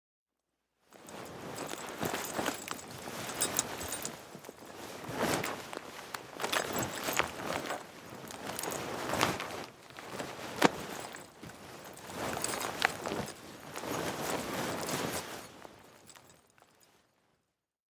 Звуки ползания
Шорох ползущего солдата в армейской амуниции